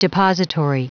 Prononciation du mot depository en anglais (fichier audio)
Prononciation du mot : depository